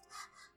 groans_04.mp3